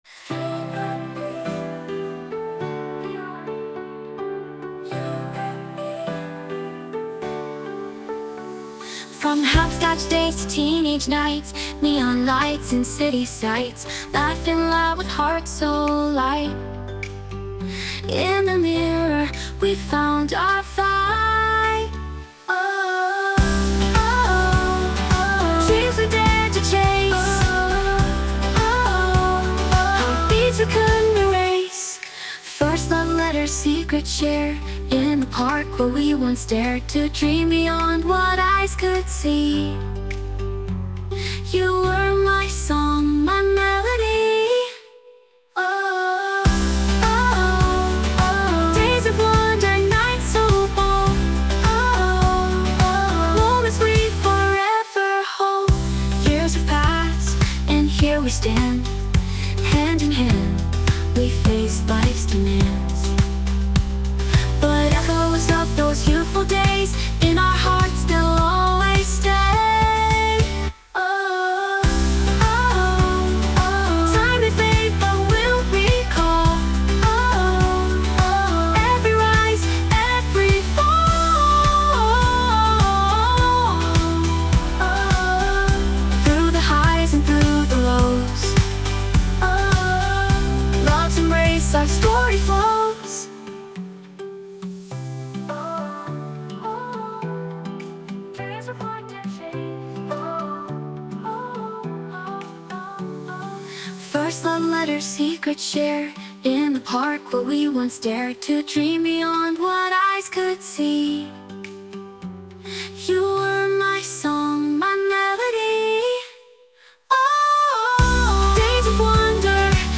洋楽女性ボーカル著作権フリーBGM ボーカル
英語・女性ボーカル曲です。